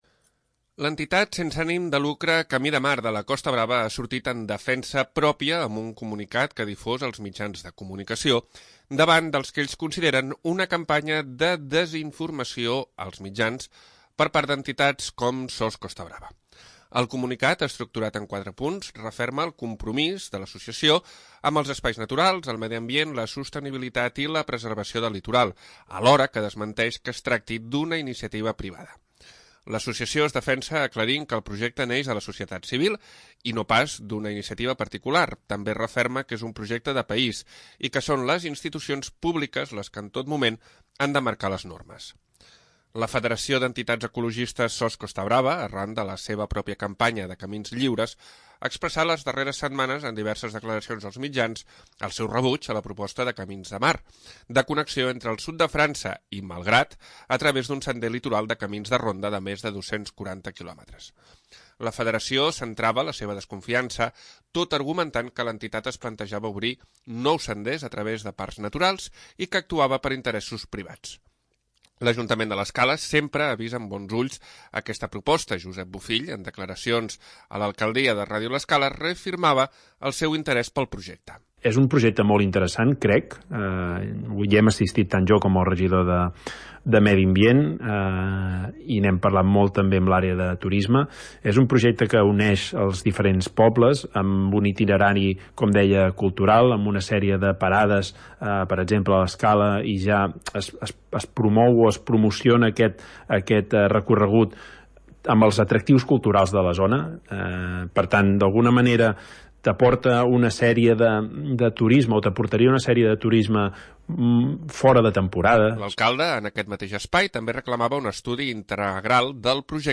L'Ajuntament de l'Escala sempre ha vist amb bons ulls aquesta proposta, Josep Bofill en declaracions a l'alcaldia de Ràdio l'Escala, reafirmava el seu interès pel projecte.